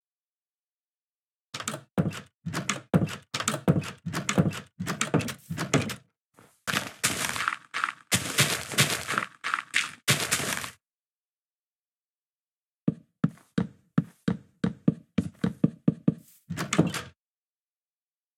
Just toggle HRTF in the sound options and hear the difference - there is a difference in the sound when you click on the UI in 1.20.1, but no difference in 1.20.2.{*}{*}
HRTF turned off.m4a